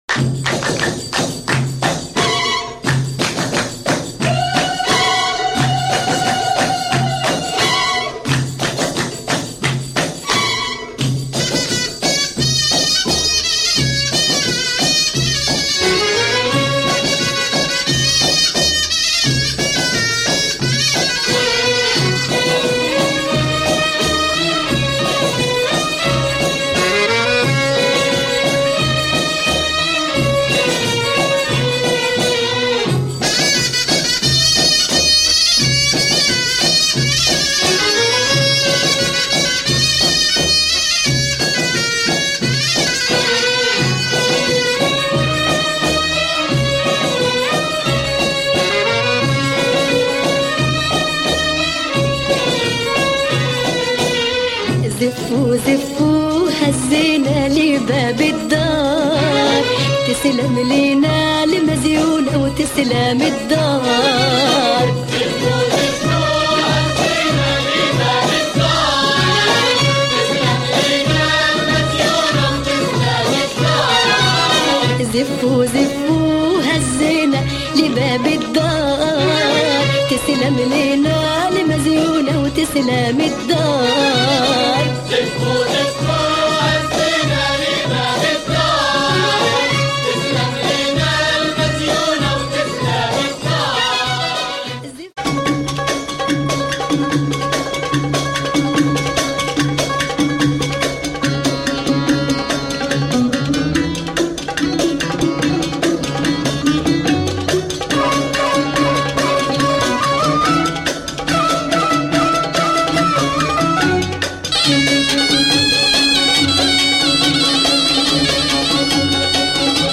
Killer oriental beats here !
Arabic & Persian